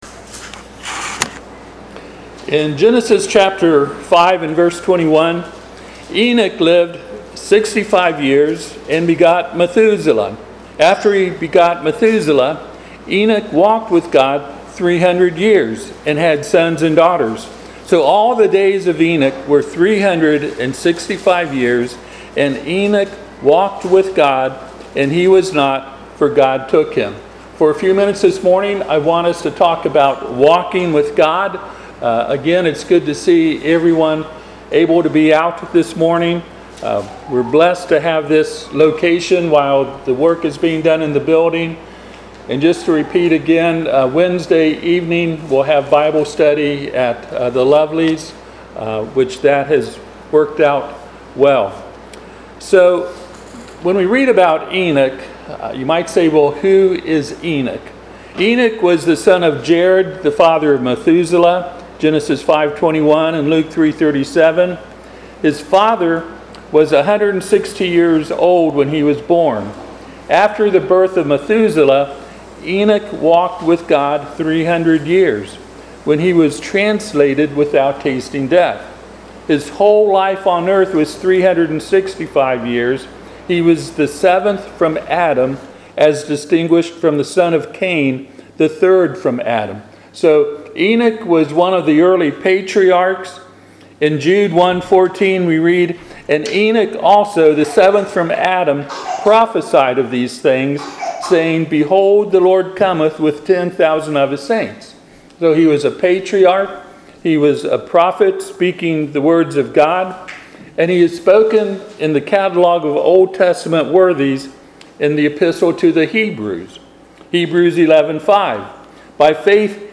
Passage: Genesis 5:21-24 Service Type: Sunday AM